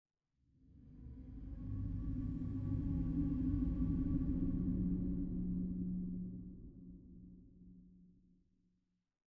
Minecraft Version Minecraft Version latest Latest Release | Latest Snapshot latest / assets / minecraft / sounds / ambient / nether / crimson_forest / voom1.ogg Compare With Compare With Latest Release | Latest Snapshot